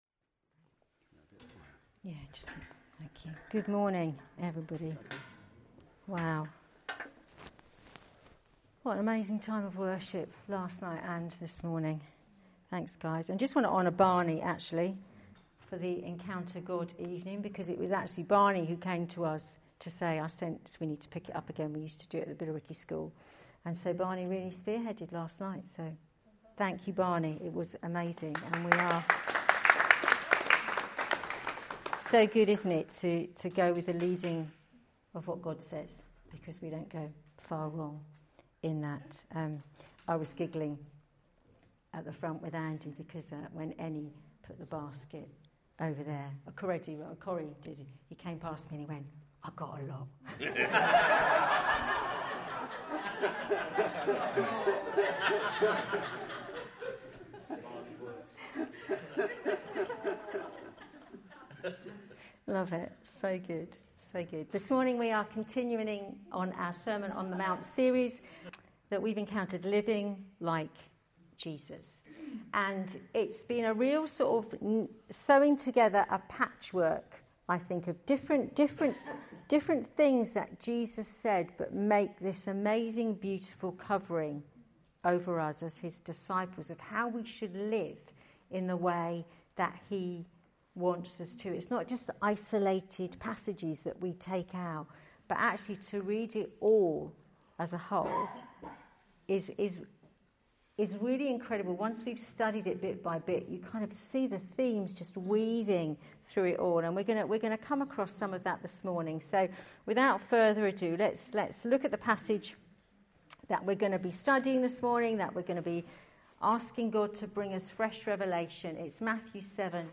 A teaching series based on The Sermon on the Mount and entitled, 'Living Like Jesus'.
Teachings from our Sunday that don’t form part of a series.